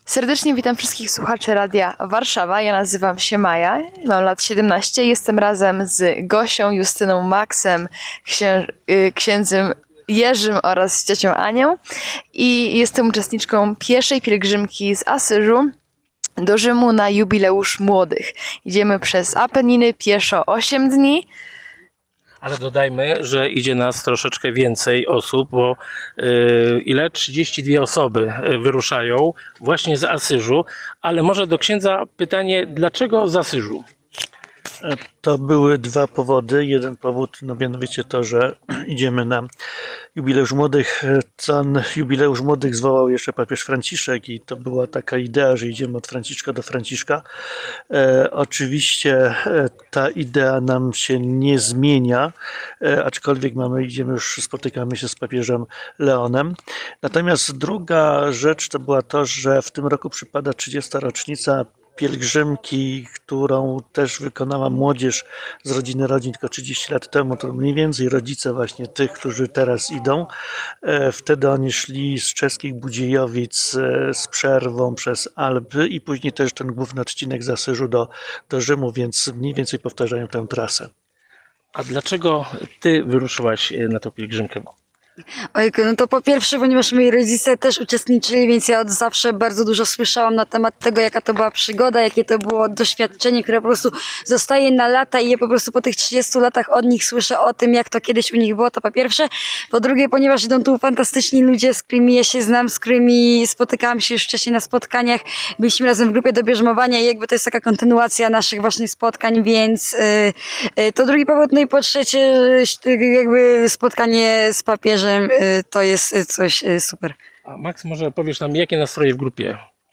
Najnowsza rozmowa z uczestnikami